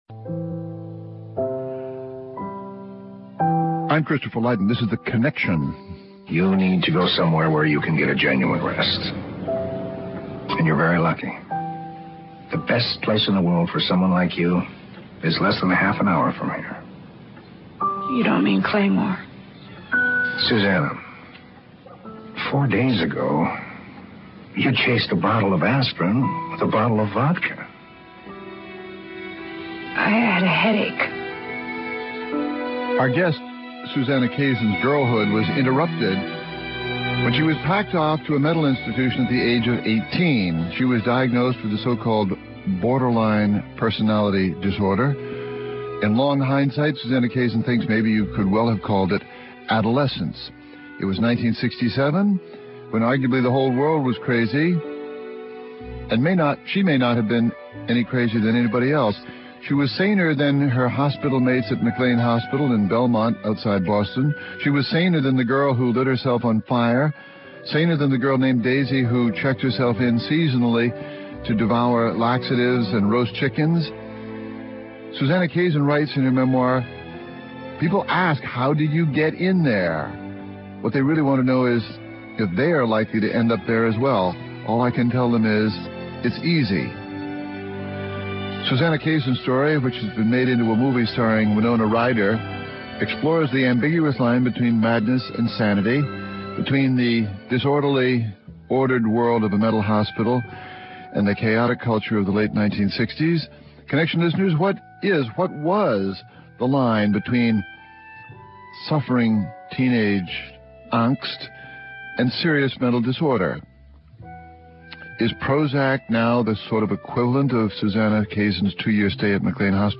Guests: Susanna Kaysen, author of “Girl Interrupted”